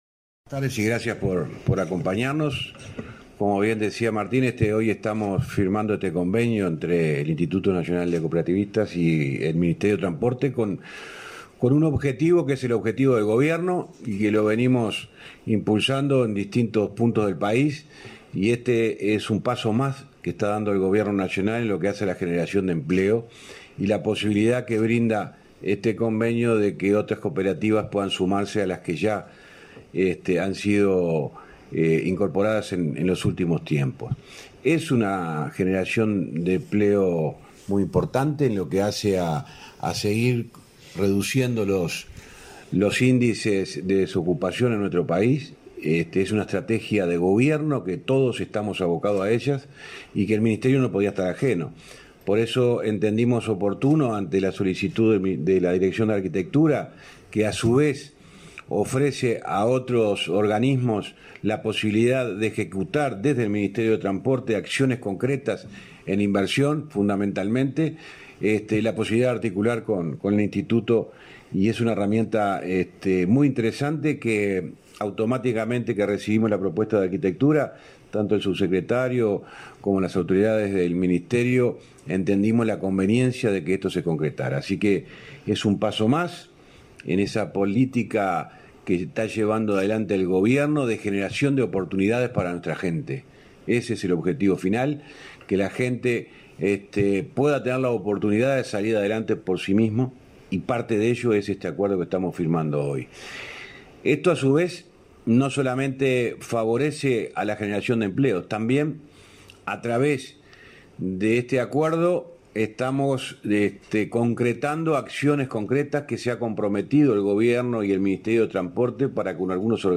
Conferencia de prensa por la firma de convenio de cooperación entre MTOP e Inacoop